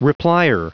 Prononciation du mot replier en anglais (fichier audio)
Prononciation du mot : replier